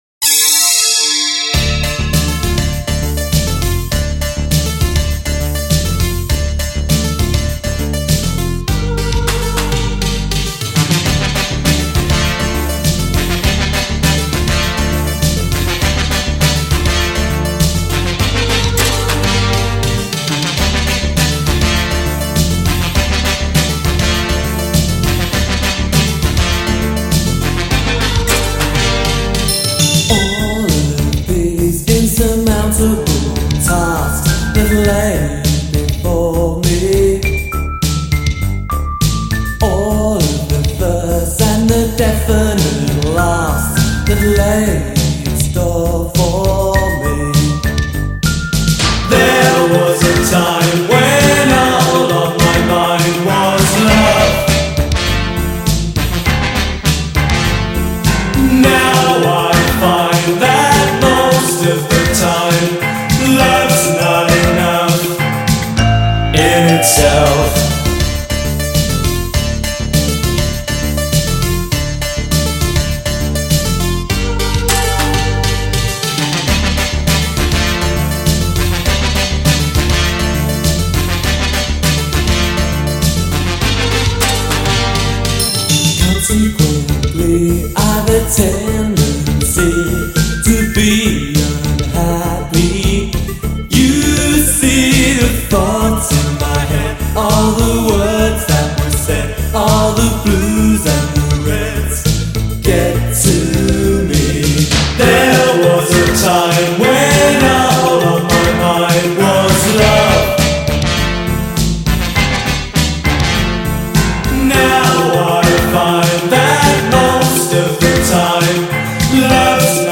Synth-Pop, Industrial, New Wave